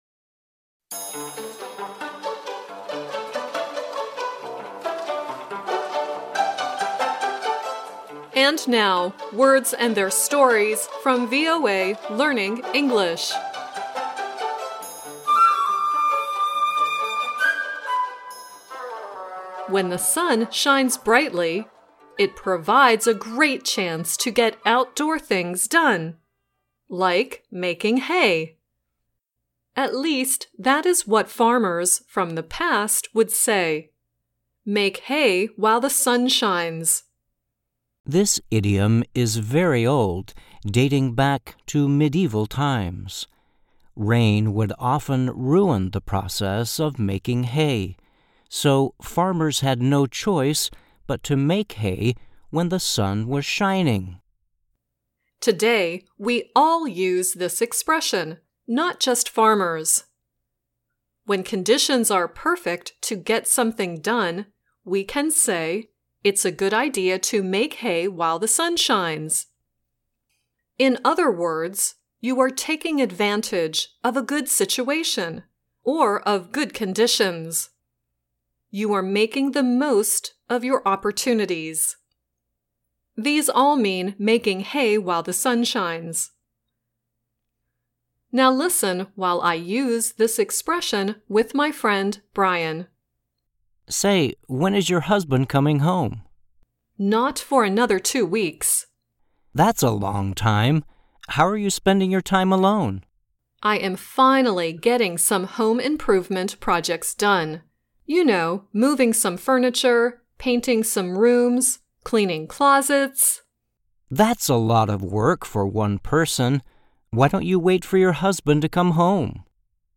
The song at the is Jessica Andrews singing "There's More to Me Than You."